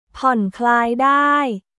ผ่อนคลายได้　ポーンクラーイ・ダイ